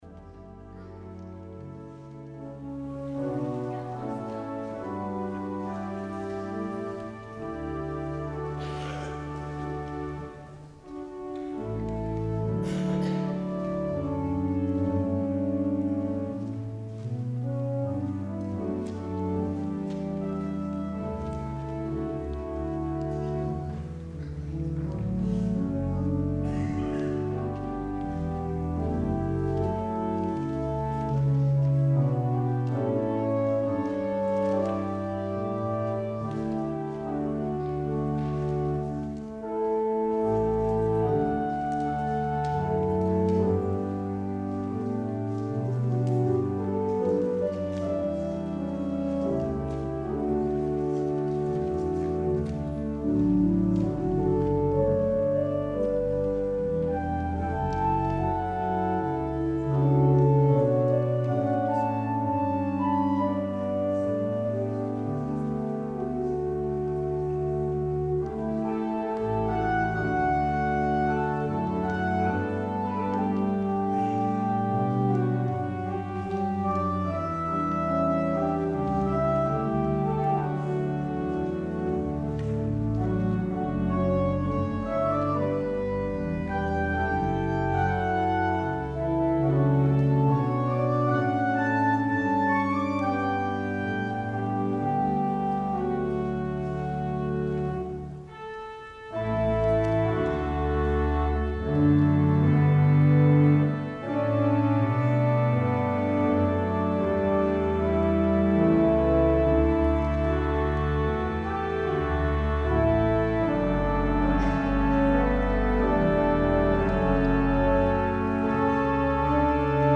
PRELUDE
organ